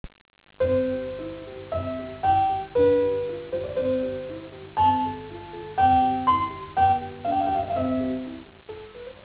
ホモフォニーとは例えば主声部の旋律に簡単な伴奏をつけた技法のようなものをさします。
ホモフォニー例 W.A.Mozart：ソナタK545から